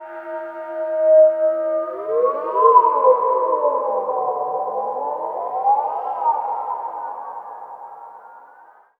WHALETALK.wav